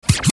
dj-scratch-sharp-one-shot_125bpm_C_minor.wav